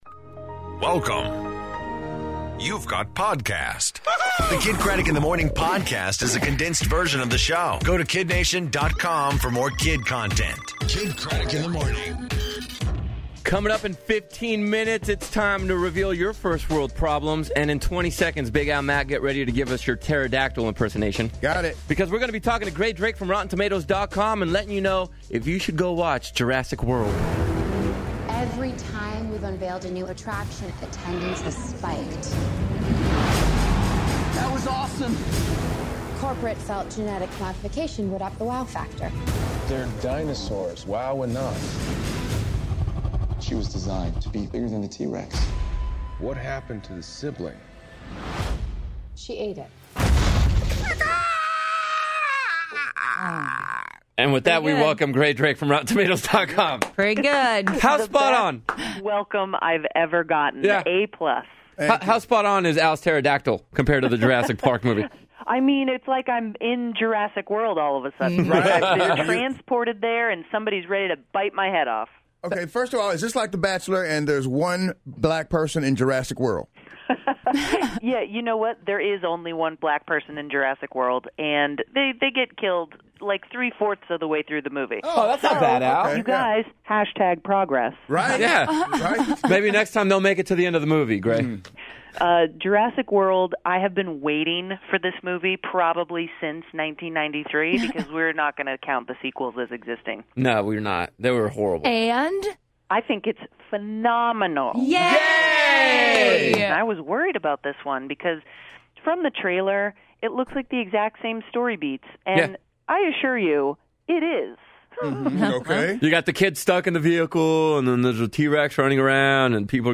First World Problems, I Can Do That, And Dave Coulier In Studio!